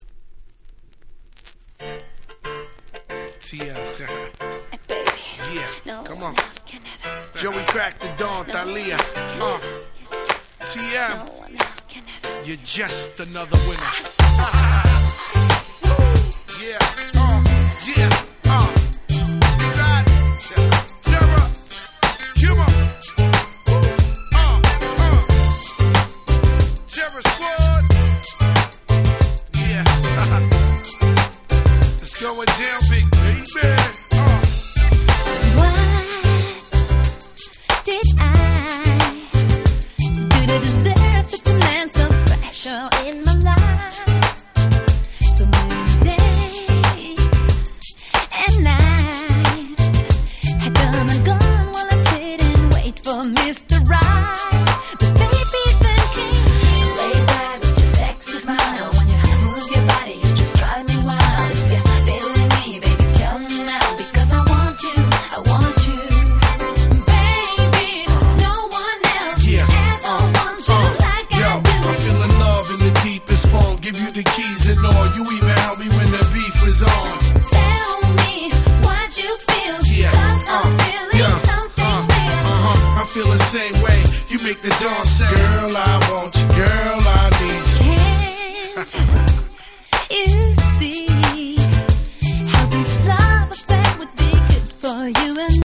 問答無用の00's R&B Classic !!
程良くキャッチーで使い易くて最高です！